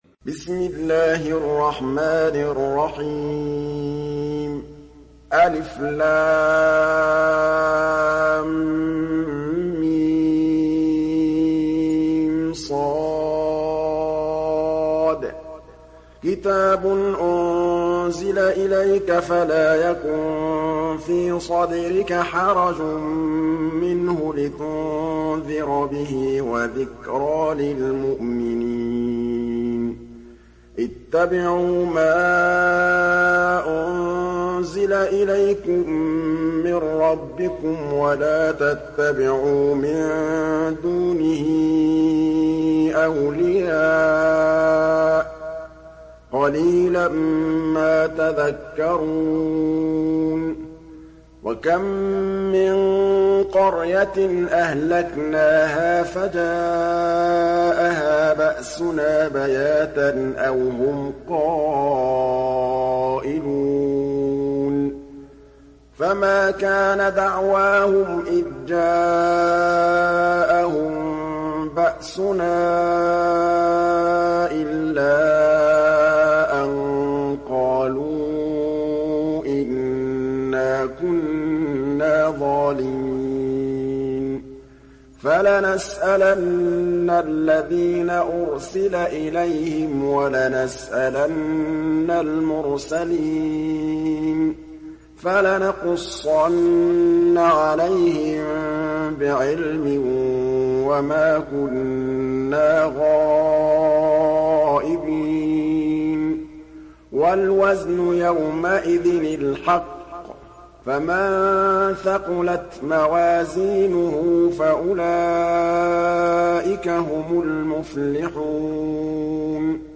Surat Al Araf mp3 Download Muhammad Mahmood Al Tablawi (Riwayat Hafs)